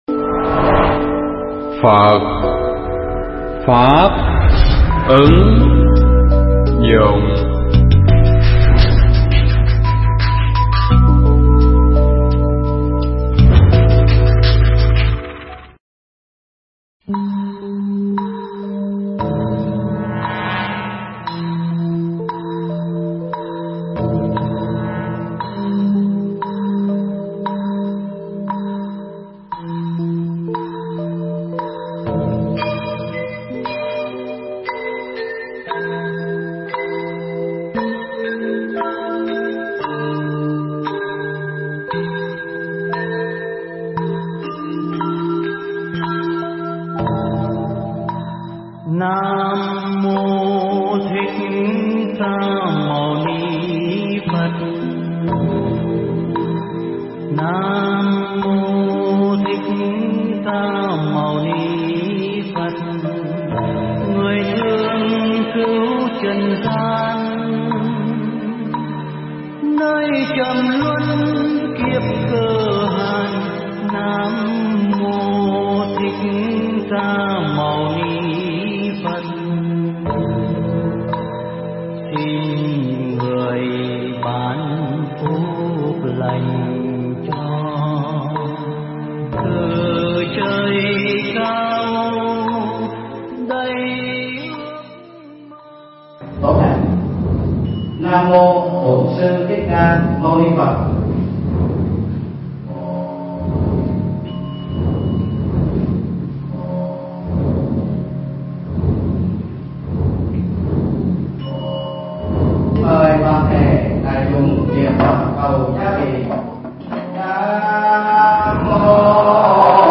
thuyết pháp
giảng tại đạo tràng Bát Quan Trai chùa Từ Tân năm 2009